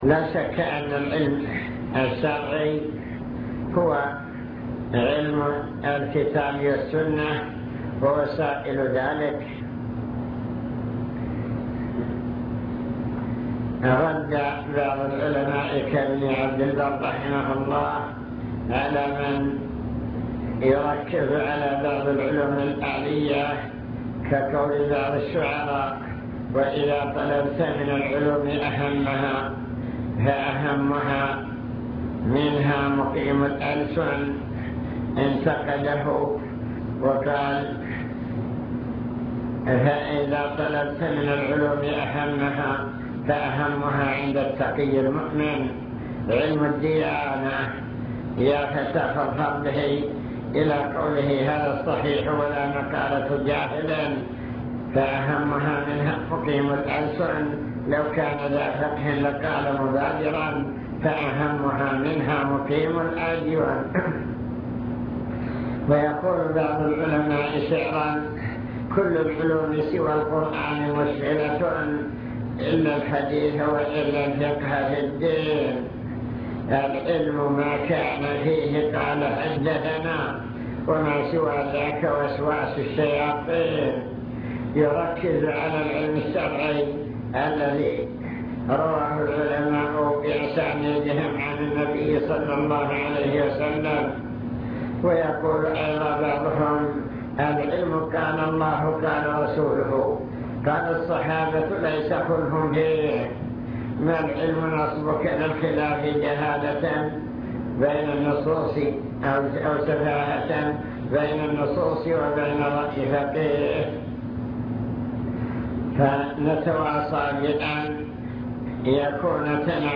المكتبة الصوتية  تسجيلات - لقاءات  لقاء إدارة التعليم